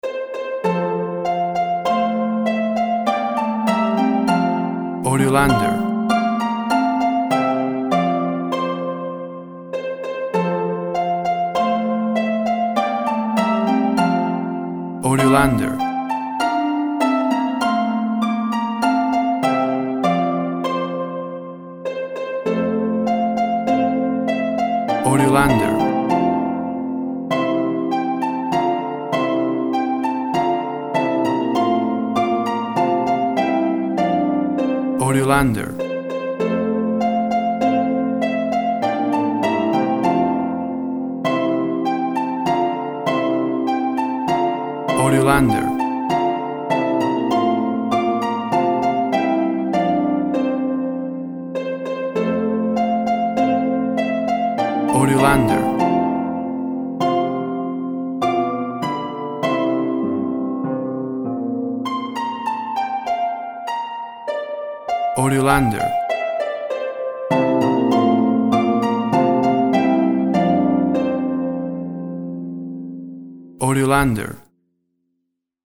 A traditional harp rendition
Full of happy joyful festive sounds and holiday feeling!
Tempo (BPM) 100